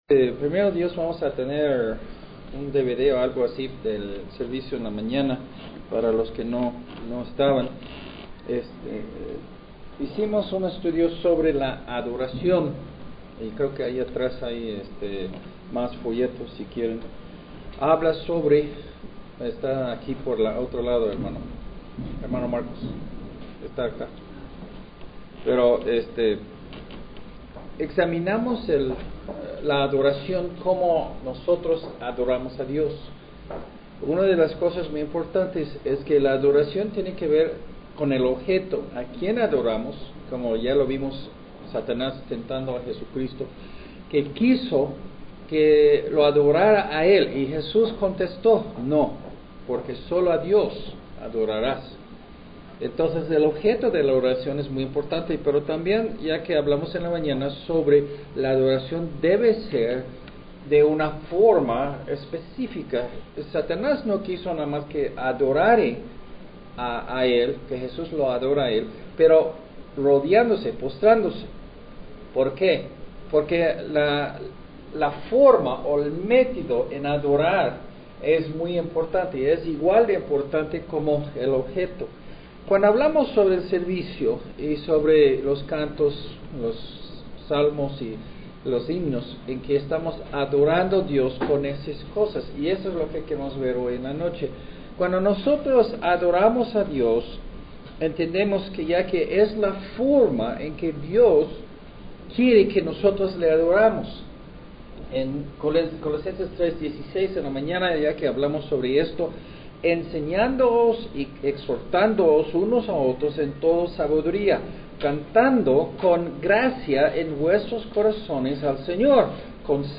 Sermón en audio